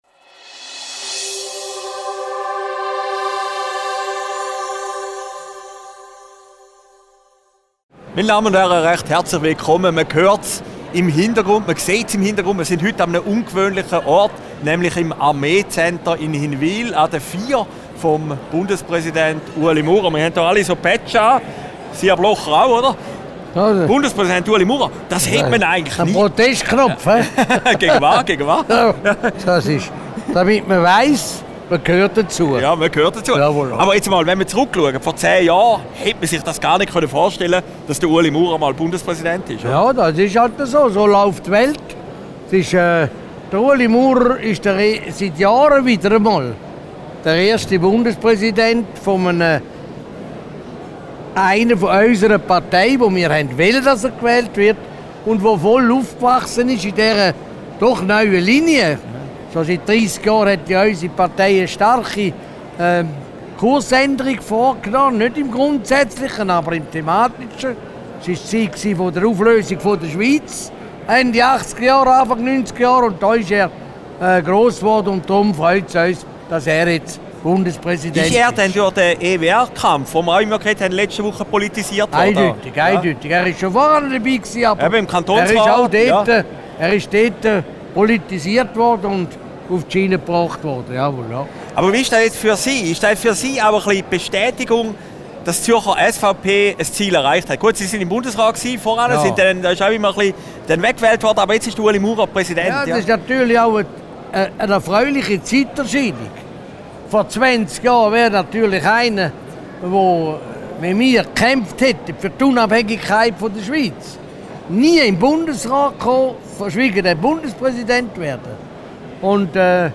Video downloaden MP3 downloaden Christoph Blocher über die Bundespräsidentenfeier für Ueli Maurer und den Sonderzug nach Hinwil Aufgezeichnet im Armeelogistikcenter Hinwil, 13.